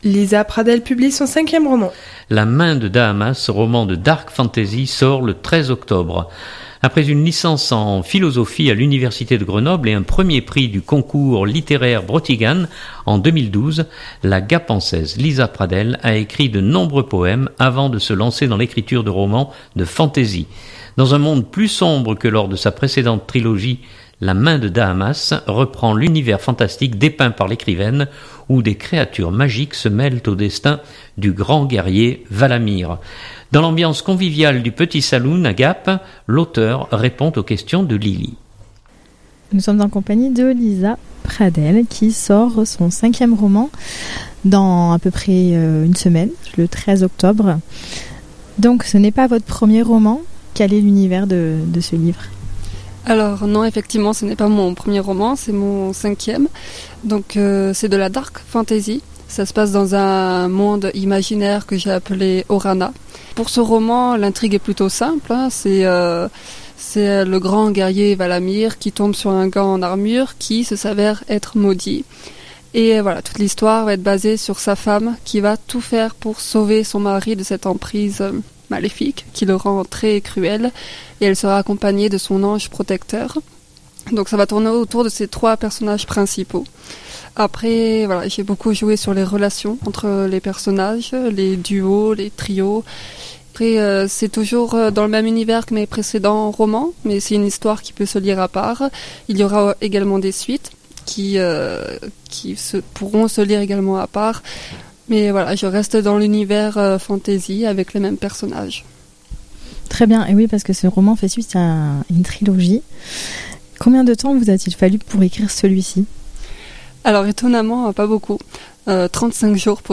Dans l’ambiance conviviale du Petit Saloon